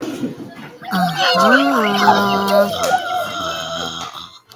Weird Noises 11 Sound Effect Download: Instant Soundboard Button